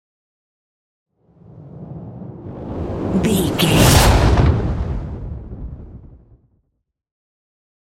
Dramatic whoosh to hit trailer
Sound Effects
Atonal
intense
tension
woosh to hit